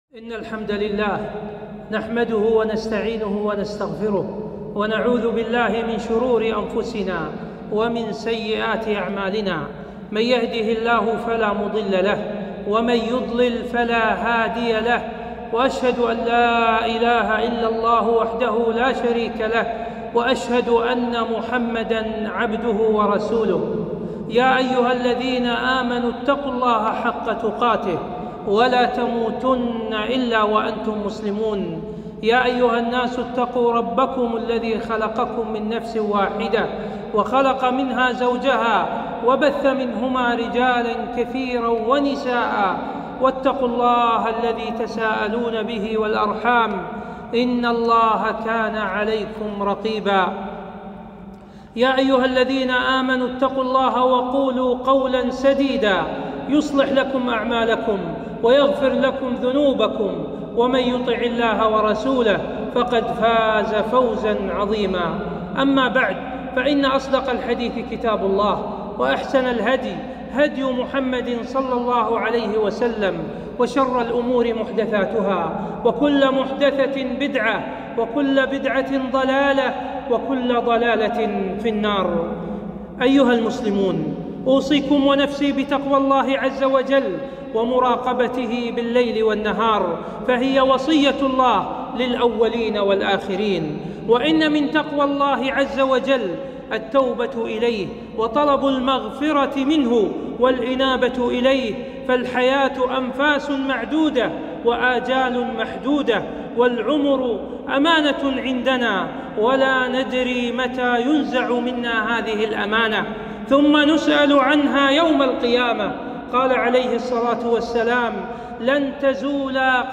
خطبة - التوبة وشروطها